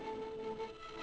violin
Added violin